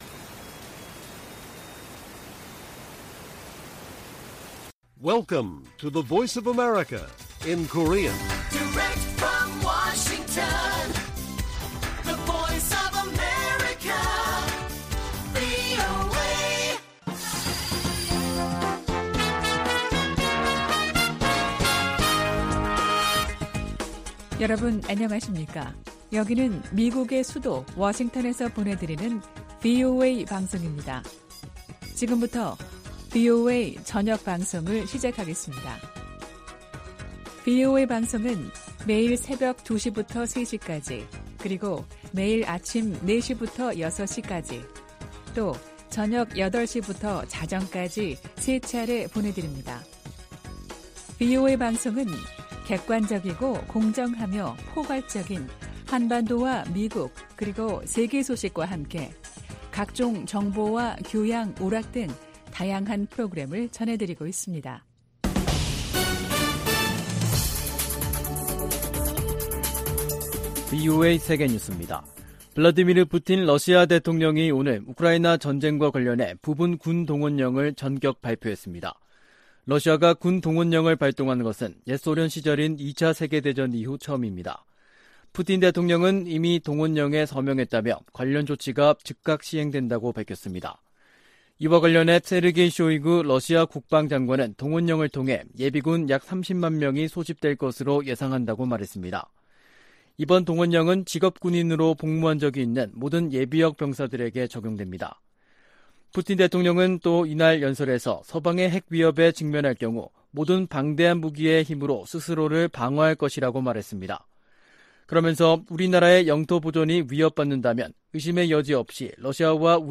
VOA 한국어 간판 뉴스 프로그램 '뉴스 투데이', 2022년 9월 21일 1부 방송입니다. 조 바이든 미국 대통령이 유엔총회 연설에서 유엔 안보리 개혁의 필요성을 강조할 것이라고 백악관이 밝혔습니다. 윤석열 한국 대통령은 유엔총회 연설에서 자유를 지켜야 한다고 역설했습니다. 미국 정부가 북한인권특사 인선을 조만간 발표할 것으로 기대한다고 성 김 대북특별대표가 말했습니다.